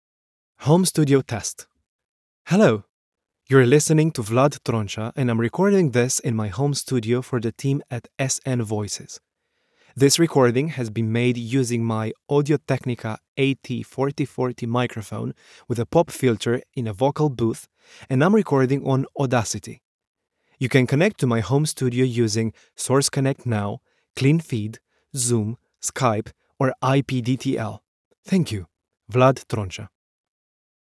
Bi-Lingual Romanian/English, Russian, Versatile, Expressive